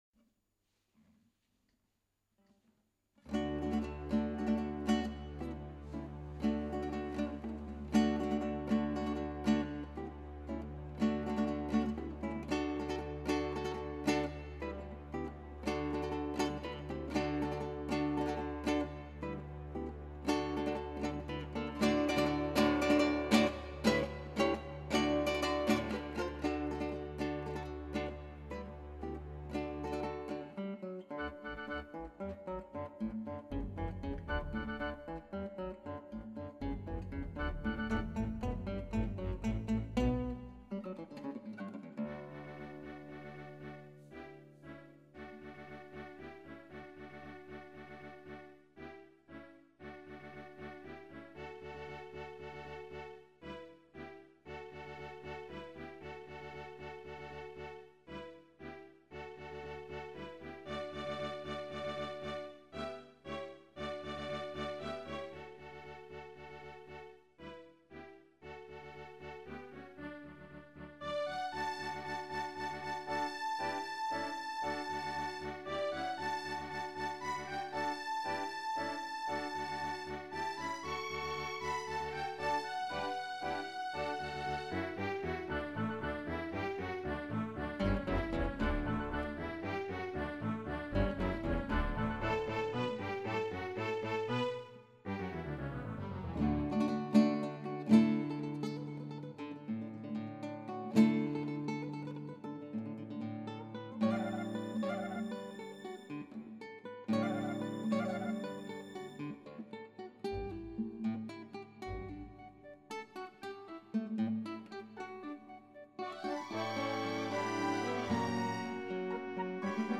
クラシックギター　ストリーミング　コンサート
へなちょこオーケストラです。ギターもへっぽこです。
兄に作ってもらったＤＴＭなんだけど音源がチープなのしかないんで、へっぽこです。
第二楽章のときはメトロノームの部分がなかったんで、再生したのをそのままマイクで拾って録音したからヘッドホンの世話にはならなかったけど、コイツはそういうわけにはいかんのでヘッドホンしながら録音です。
途中の部分も、妙に整ってて気持ち悪いです。
で、この録音では泣く泣く楽譜どおりっぽく弾いてます。